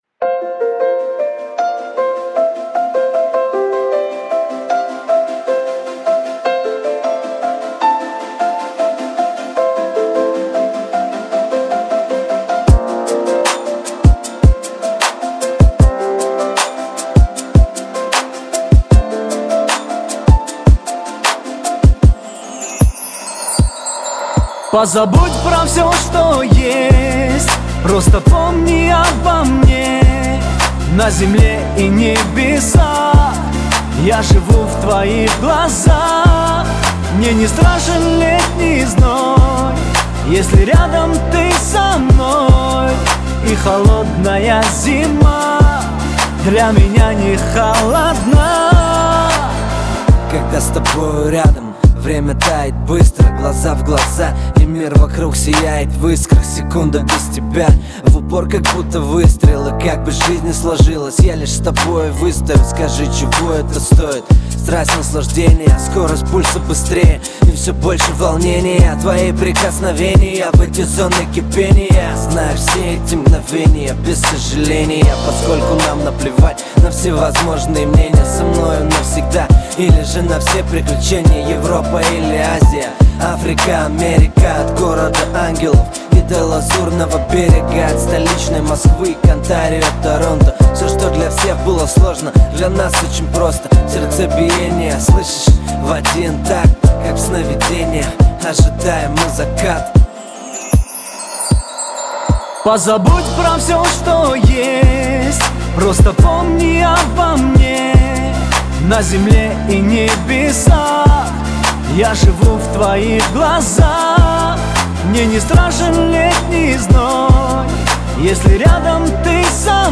это трек в жанре поп с элементами R&B